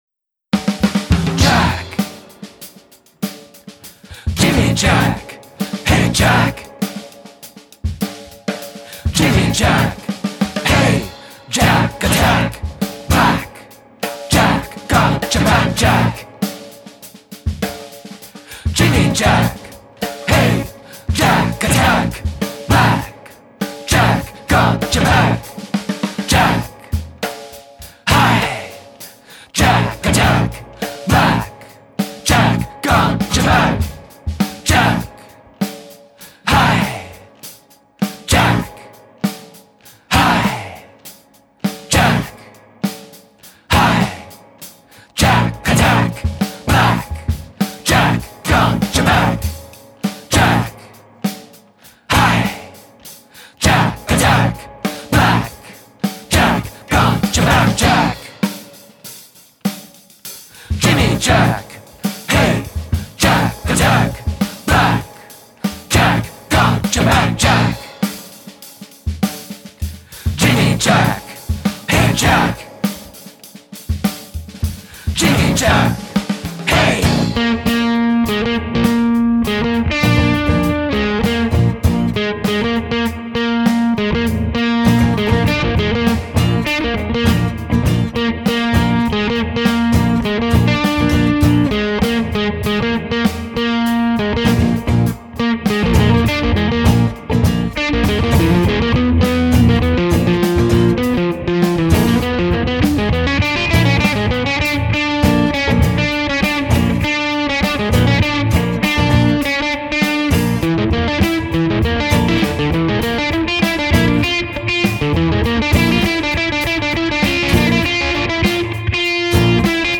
lead guitar/keyboards/vocals
guitar/piano/vocals
bass/vocals
drums/vocals